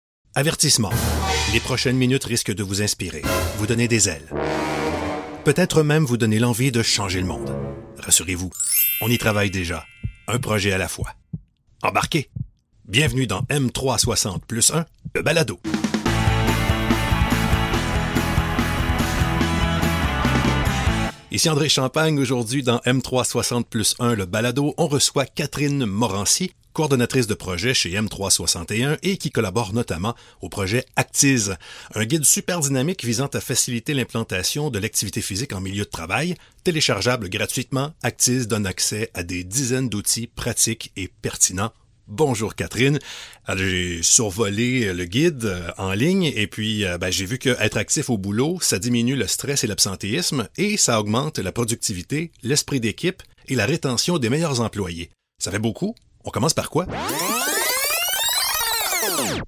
warm, authoritative French Canadian voice over with bass resonance
All this in a well-soundproofed cedar wardrobe that smells good!
Bass